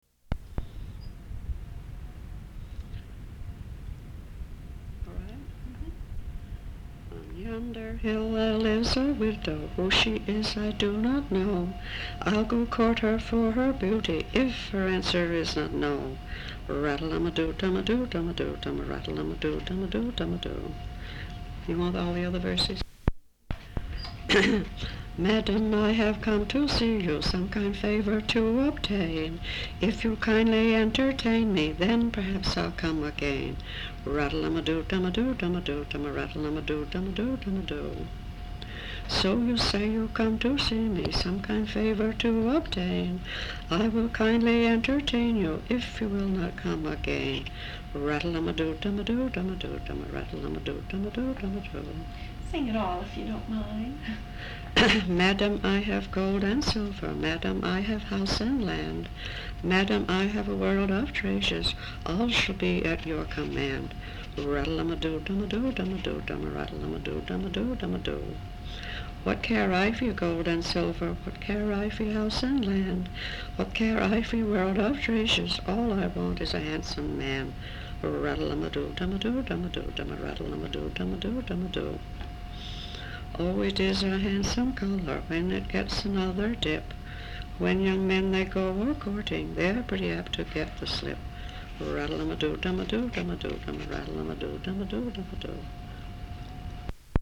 folksongs
sound tape reel (analog)